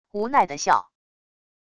无奈的笑wav音频